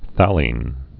(thălēn, thălē-ĭn, thālēn, thālē-ĭn, fthăl-)